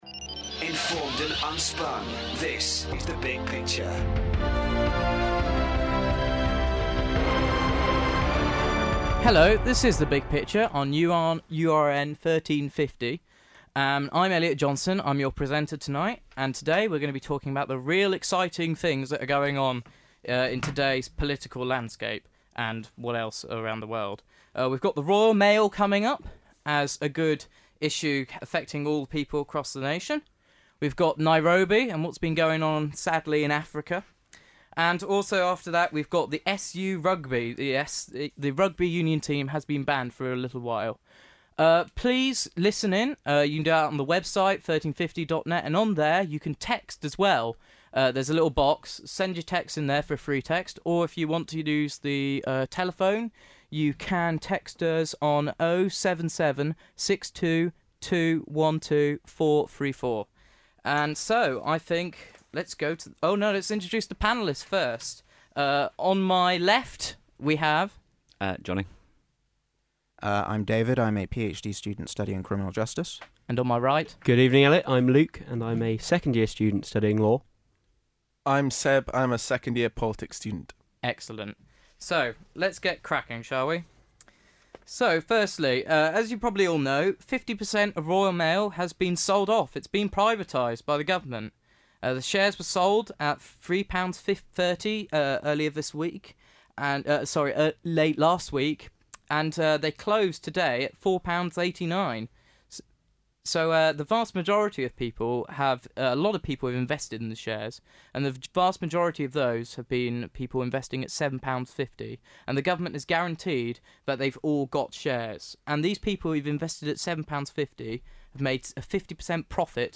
Join us for another informed and impassioned series of debates! 48:17 minutes (55.26 MB) advisors big picture contestation david cameron liberal democrats nick clegg privatisation probation Yes, include in podcast listings Download audio file